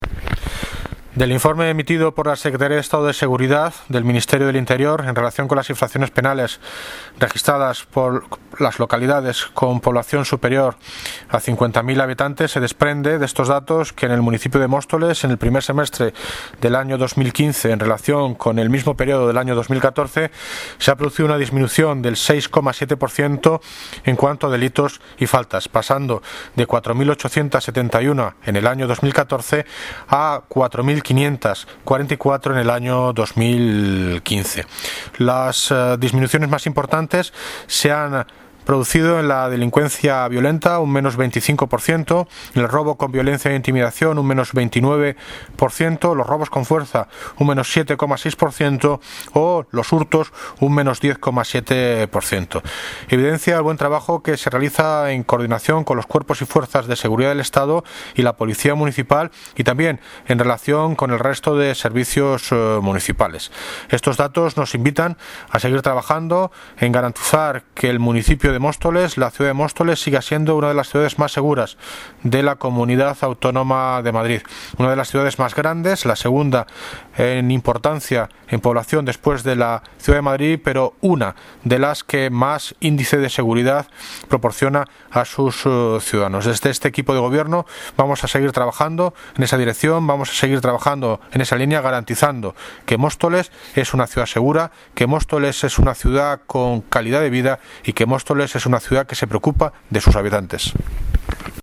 Audio - David Lucas (Alcalde de Móstoles) sobre bajada de delitos